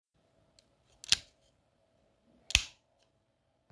Sound Effects
Switch Click
Switch.m4a